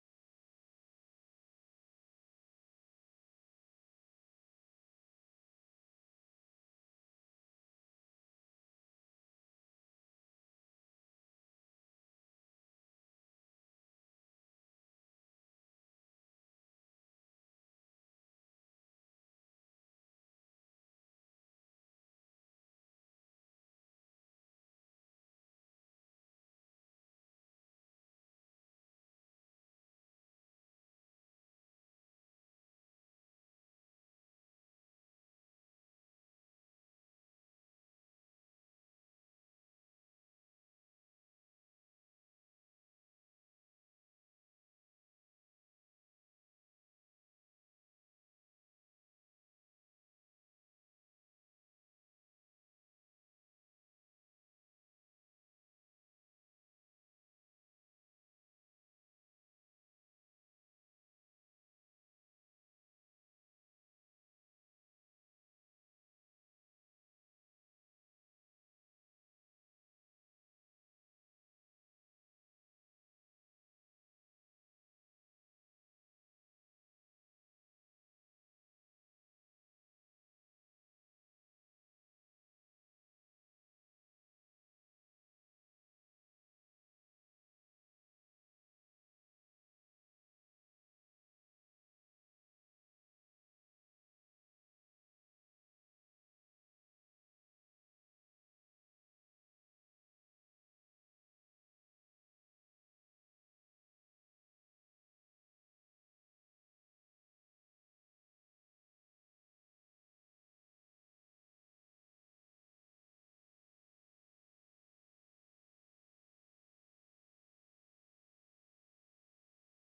Město Varnsdorf: 24. zasedání Zastupitelstva města Varnsdorf Místo konání: Lidová zahrada, Karlova 702, Varnsdorf Doba konání: 26. května 2022 od 15:00 hod.